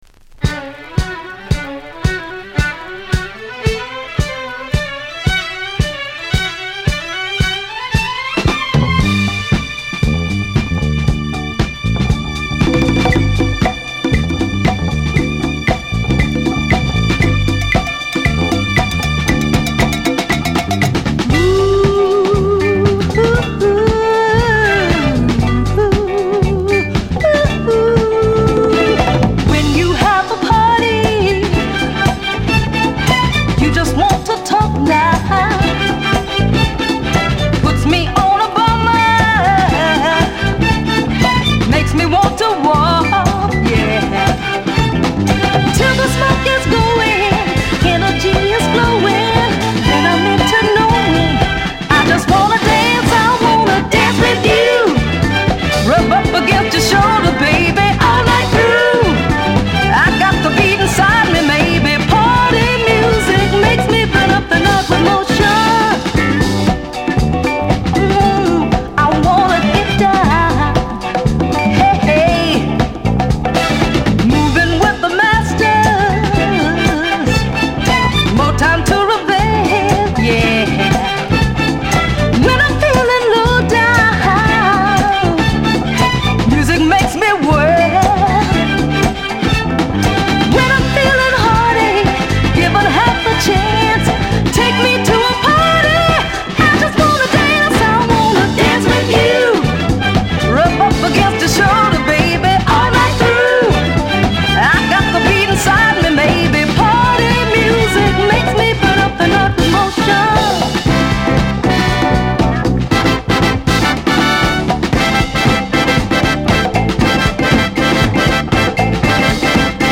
Free Soul, Disco us
華やかなストリングス、音抜けの良いパーカッションの転がり具合も絶妙な美メロ・ノーザン♪ 後半のこみ上げ感もたまりません。